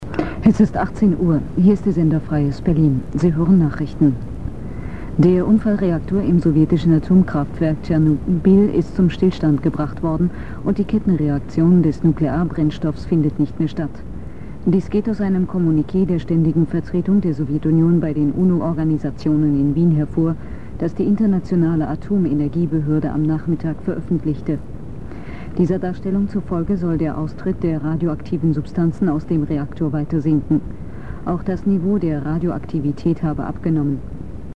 SFB - Nachrichten (0:35)
Die obenstehenden Audiodateien sind Aufnahmen auf Kassettenrekorder direkt (Radio) oder per Mikro (TV) und generell sehr dumpf und (besonders bei den Mikro-Aufnahmen) mit Rauschen unterlegt.